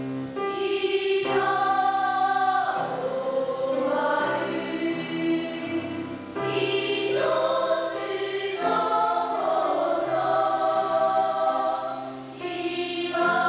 学級全員による合唱です。
聞かせどころだけを録音しました。
もとは体育館でステレオ録音したものです。
ひとつのこと ２部合唱 斉藤喜博 丸山亜希 あります